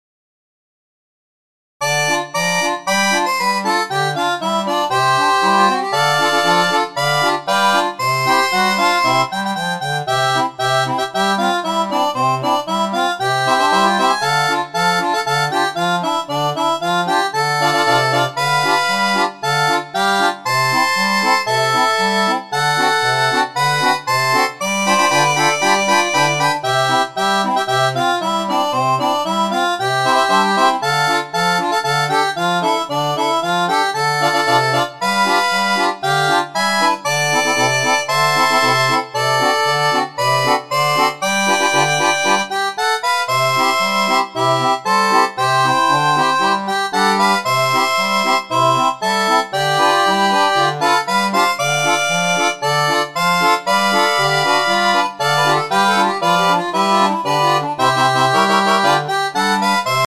DO M